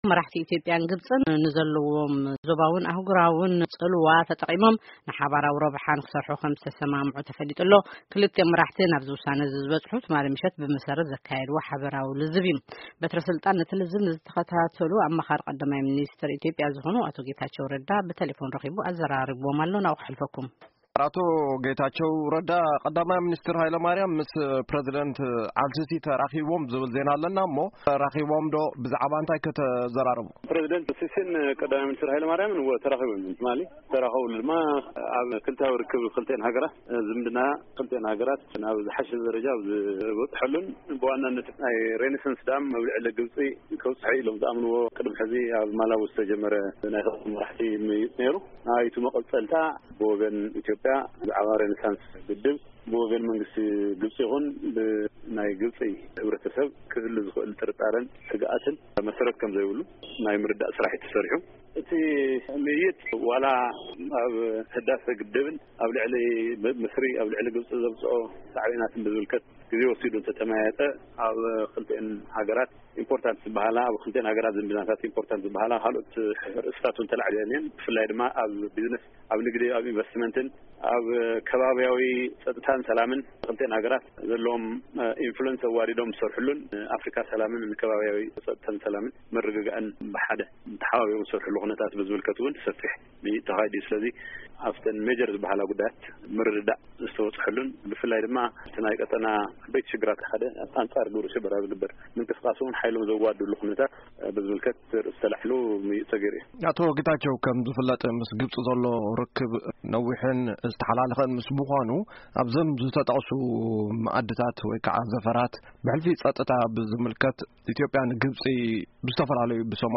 ቃለ መጠይቅ ምስ ኣቶ ጌታቸው ረዳ